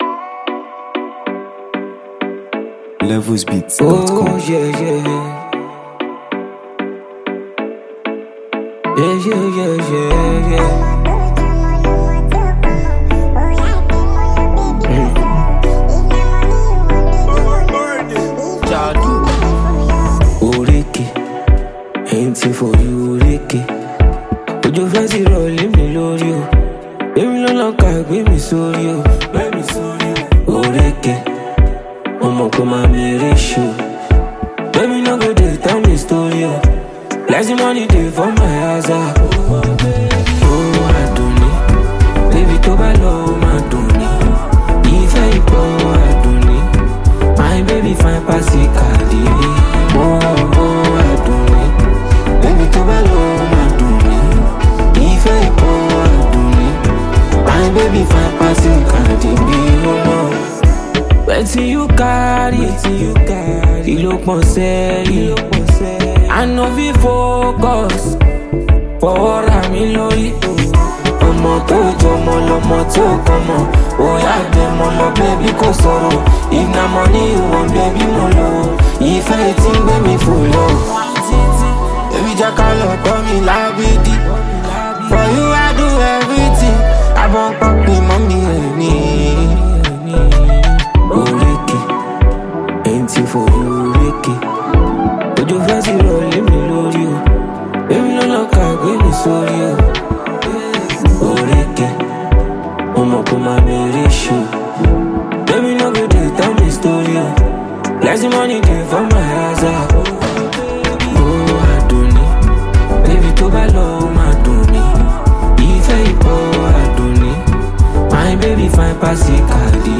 blending melodic Afrobeat rhythms with heartfelt lyrics.